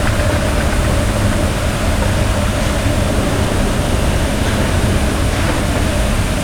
ESCALATOR_Model_01_loop_mono.wav